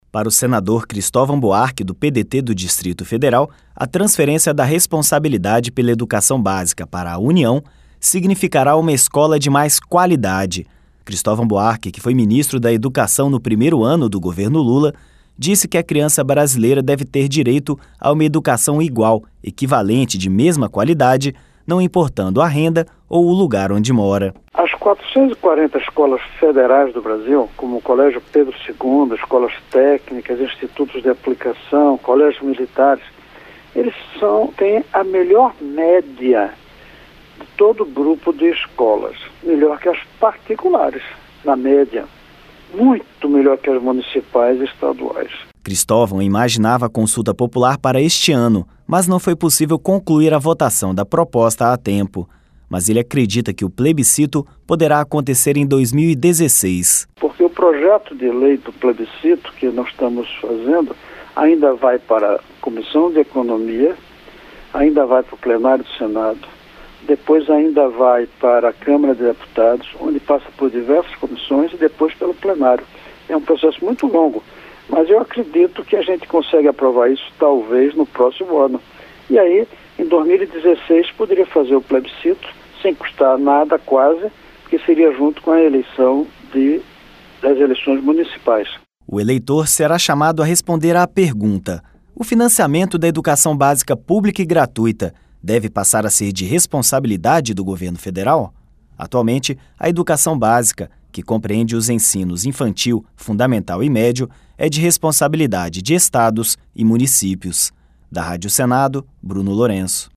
Senador Cristovam Buarque